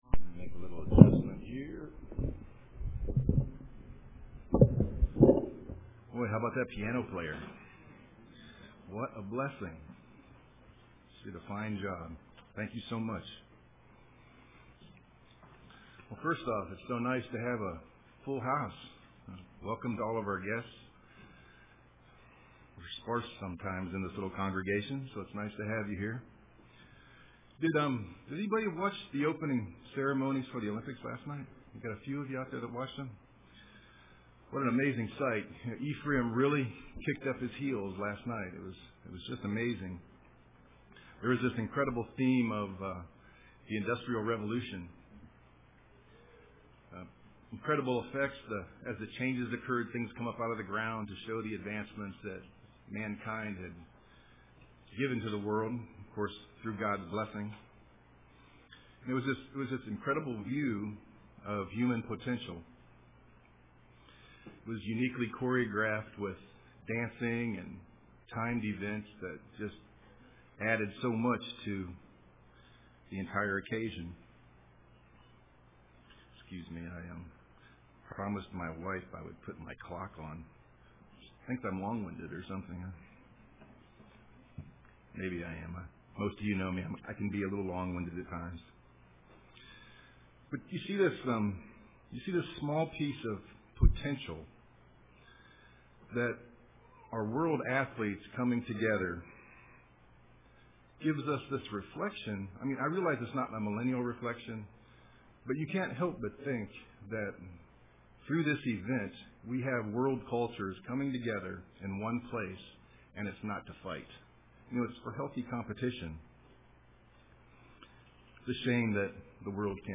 Print Striving for the Gold of Eternity UCG Sermon Studying the bible?